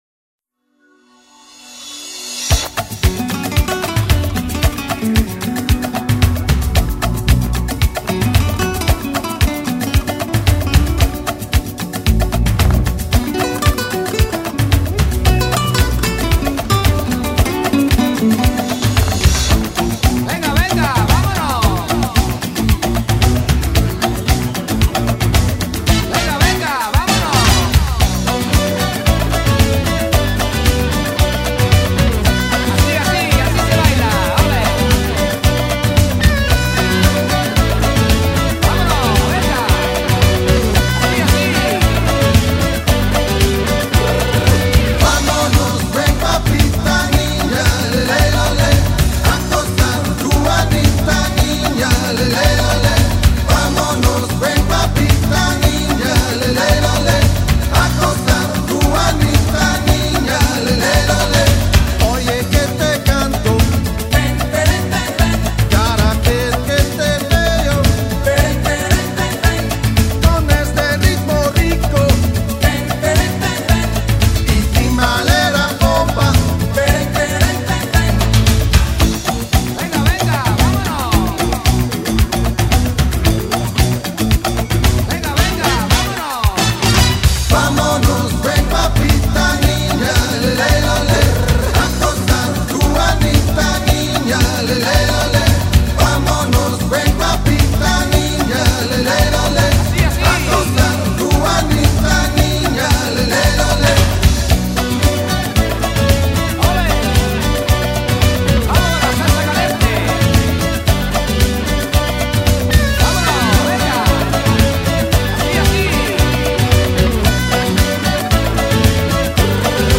都充满了超强的热情